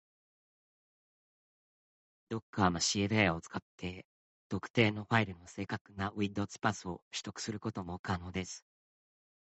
ほかにも音声変換がAIをつかって高精細におこなうことができるRVCモデルも用意されています。
ノイズやイントネーションも正確でよい感じです。
ピッチが低い女性の声になりますので、自分にあったトーンの声を選択したほうが良いでしょう。